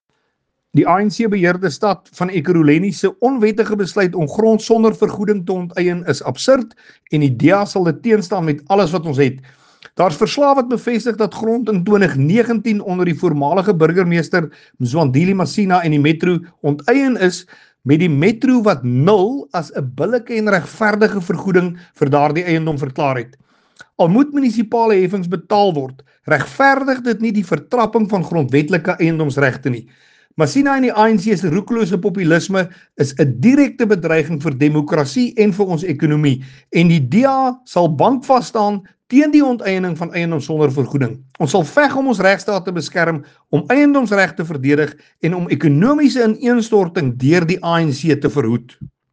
Afrikaans soundbites by Willie Aucamp MP.